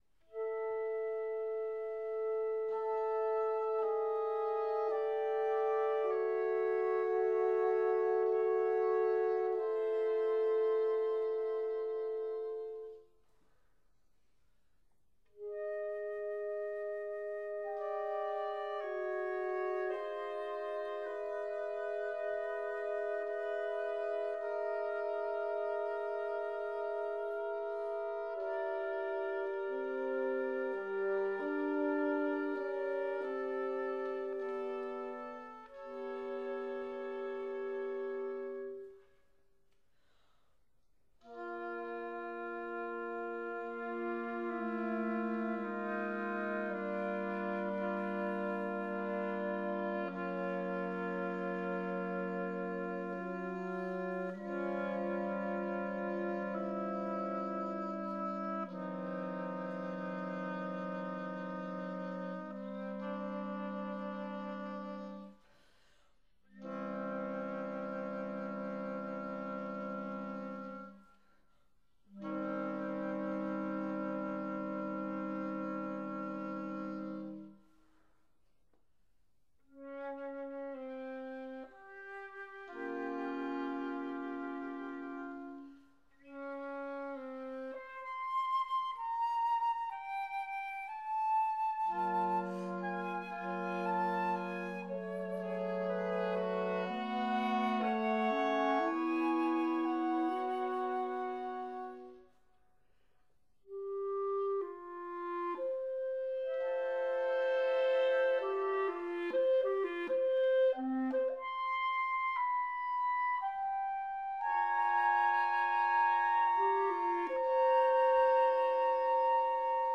flute
oboe
clarinet
bassoon